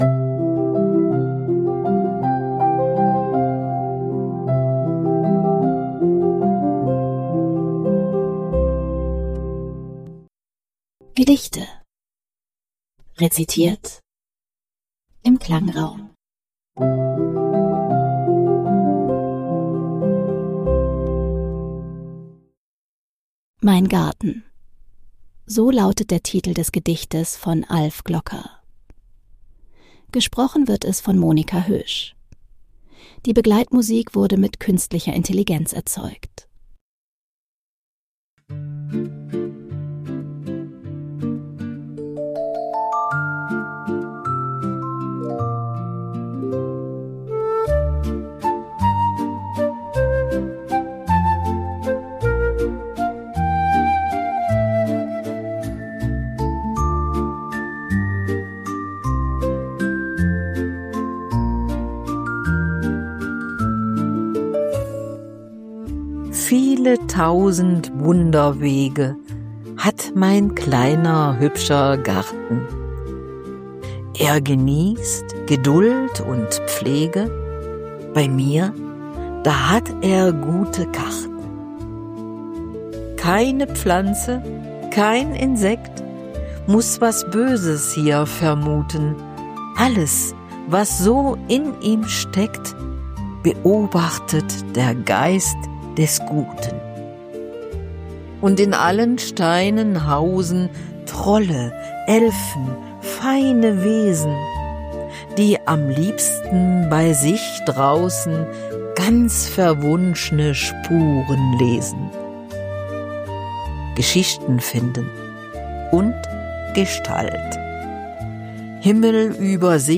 Die Begleitmusik